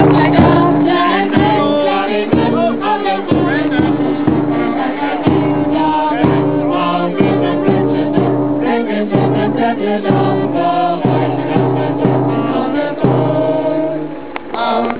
Kirmesumzug
Ein Kirmesumzug mit Gesang und guter Laune!